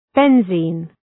Προφορά
{‘benzi:n}